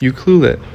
Captions English Pronunciation of "Ucluelet" by a native male speaker of Canadian English
Ucluelet_pronunciation.mp3